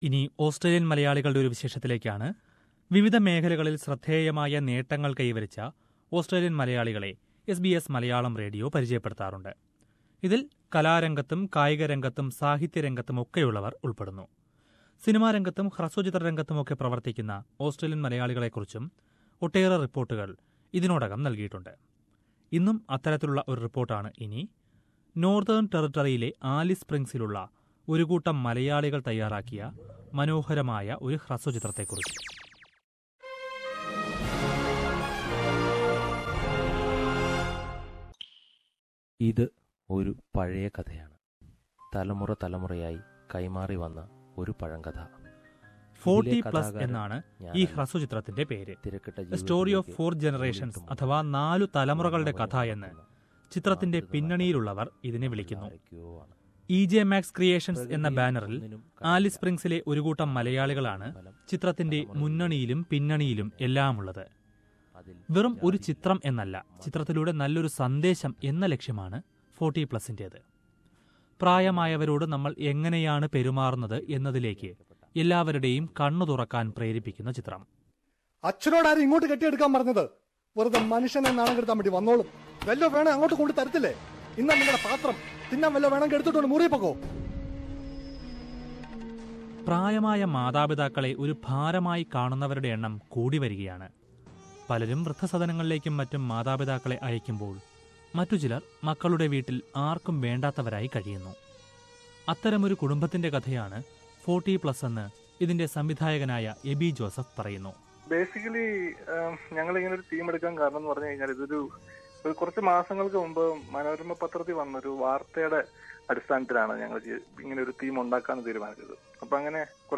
Listen to report about a short film produced by a group of Malayalees in Alice Springs.